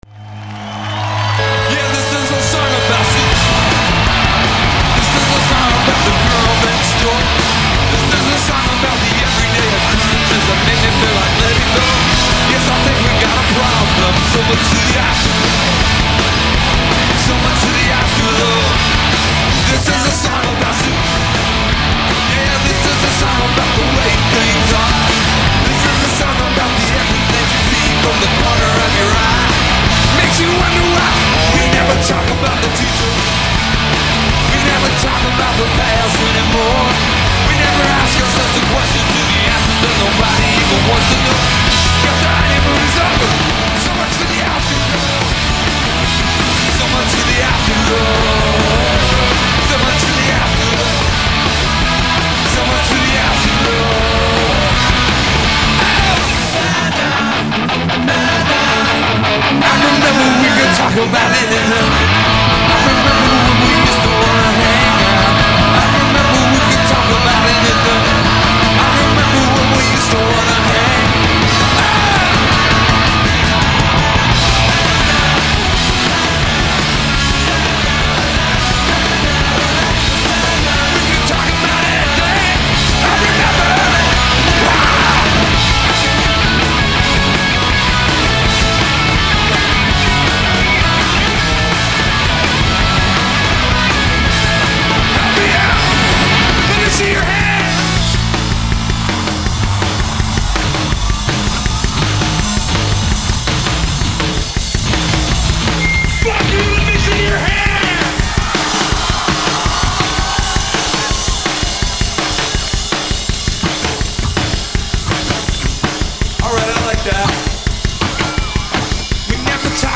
raging live version
recorded at the Roseland Ballroom in New York City